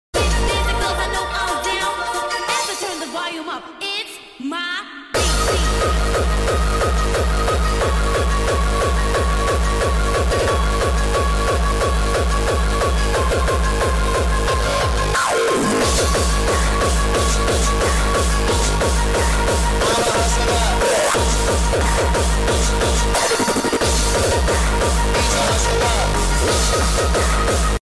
#2stroke